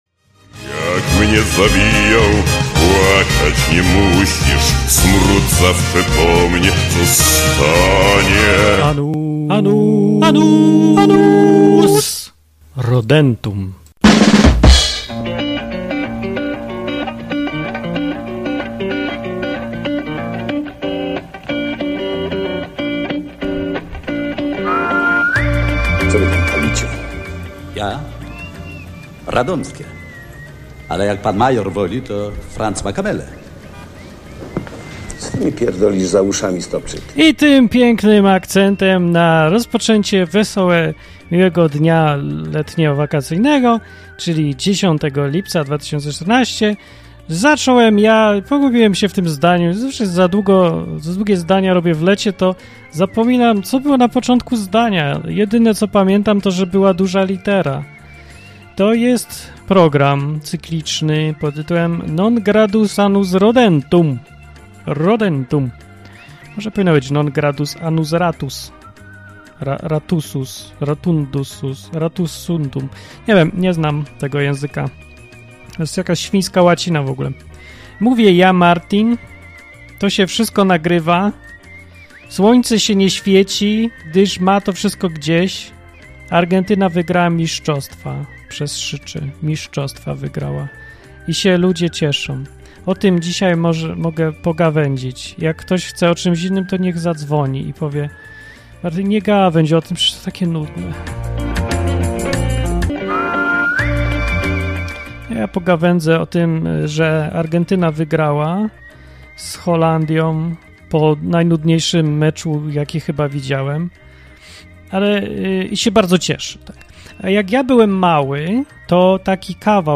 w audycji na żywo ze słuchaczami